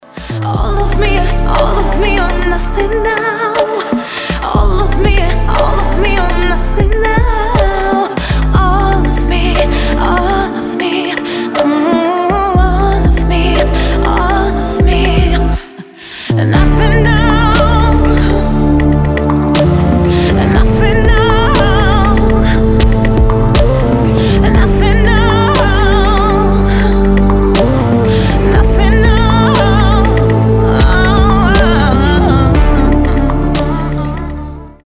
• Качество: 320, Stereo
поп
грустные
Electronic
спокойные
чувственные
медленные
Chill Trap
красивый женский вокал